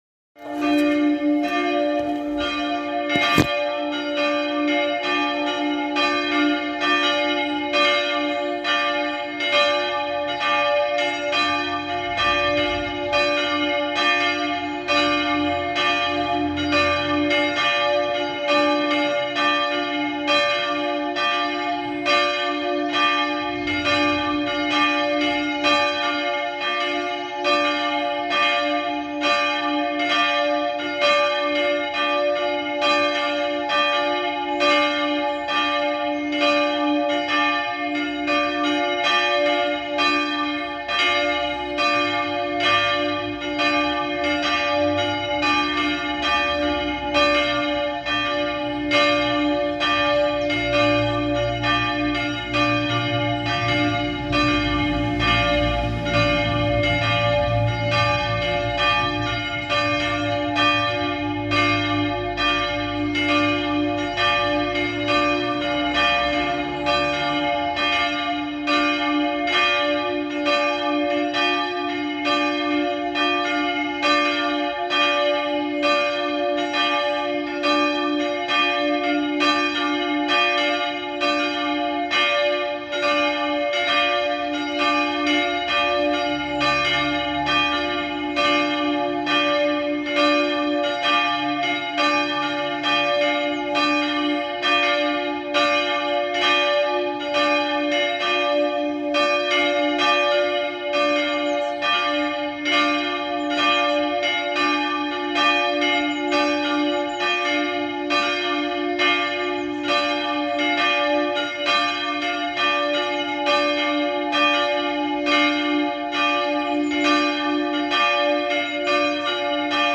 Geläut Krumbach
Glockenlaeuten.mp3